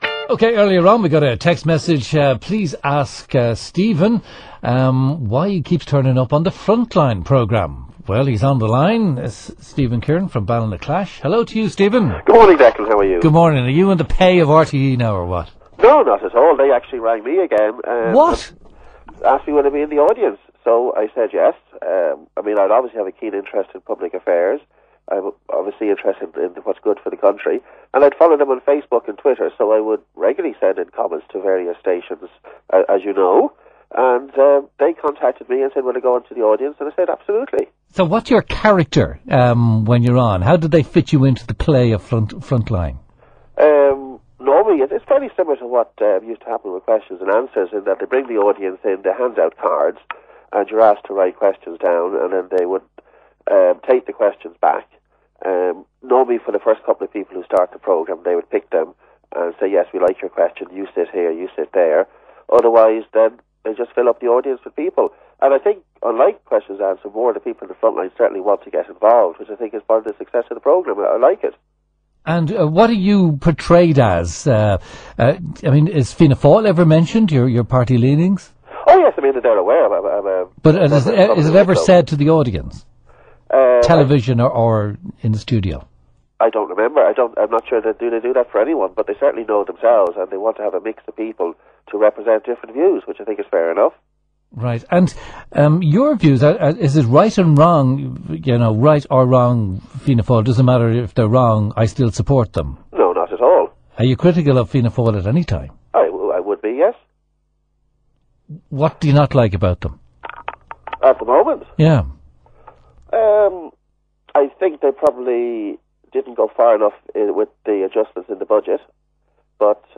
Categorized | Radio Interviews My Appearances on The Frontline Posted on 17/02/2010.